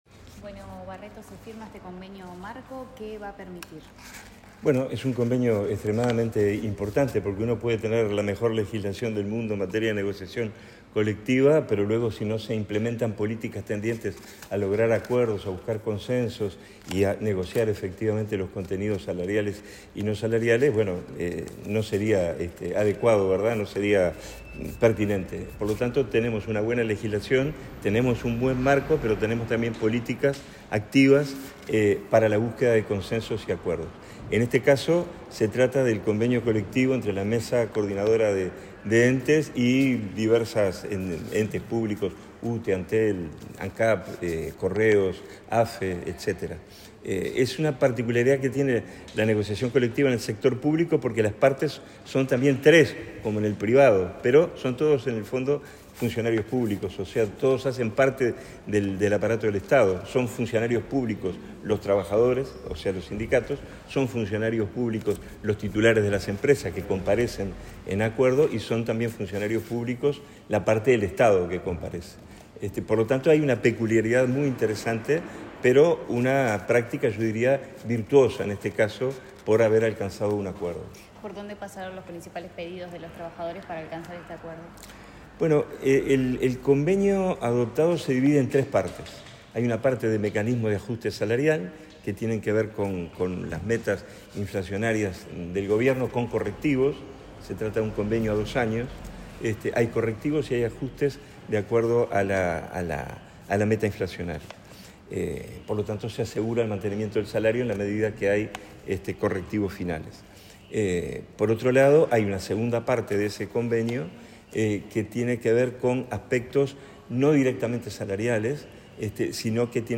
Declaraciones del subsecretario de Trabajo, Hugo Barreto
Tras la firma del convenio tripartitito con los entes públicos, el subsecretario del Ministerio de Trabajo y Seguridad Social (MTSS), Hugo Barreto,